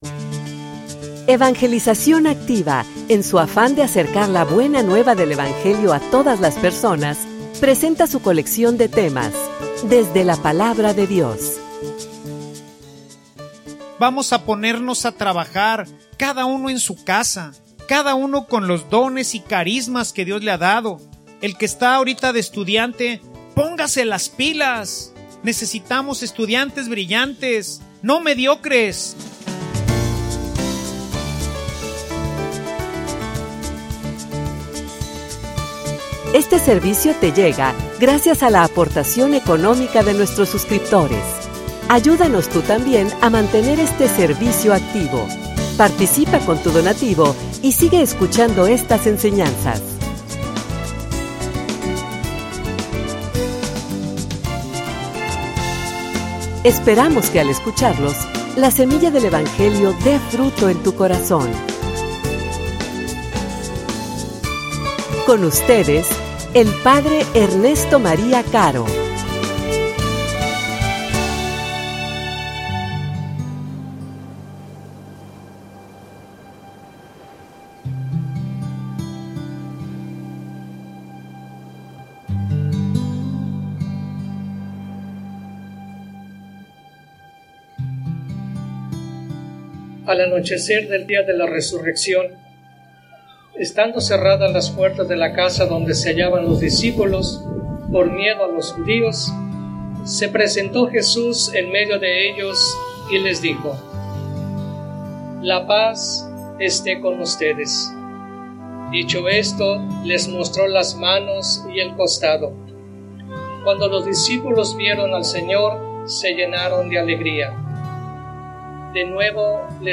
homilia_El_signo_de_la_paz.mp3